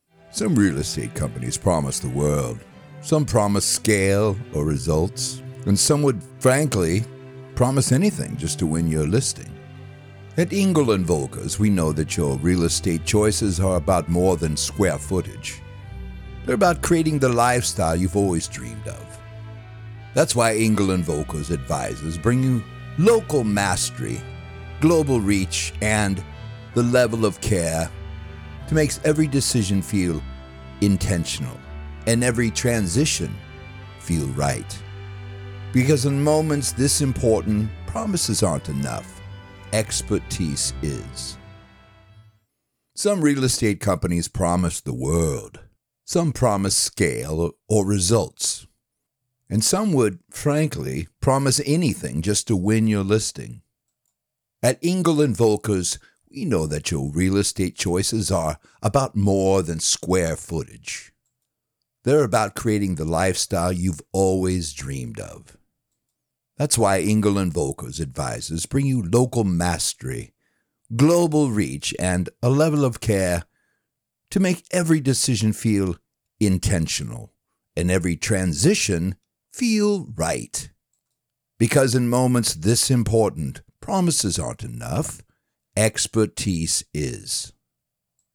Explainer & Whiteboard Video Voice Overs
Adult (30-50) | Older Sound (50+)